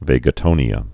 (vāgə-tōnē-ə)